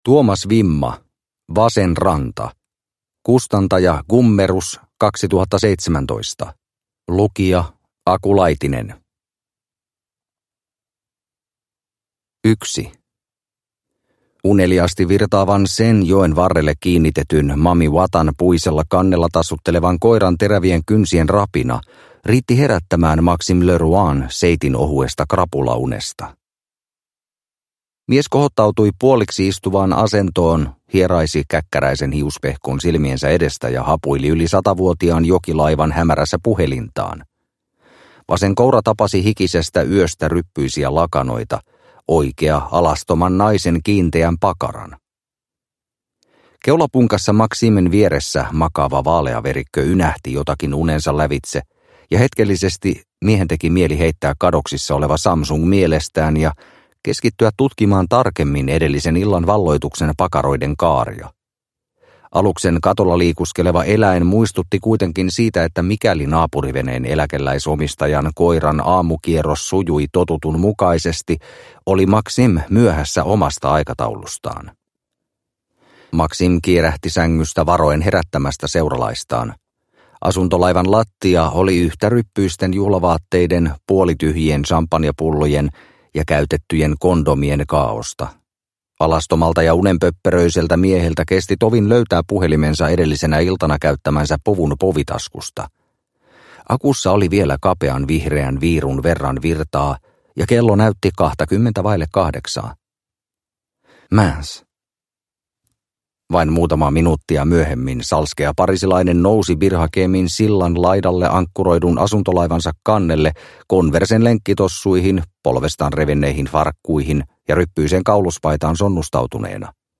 Vasen ranta – Ljudbok – Laddas ner